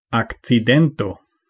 Ääntäminen
IPA : /ˈæk.sə.dənt/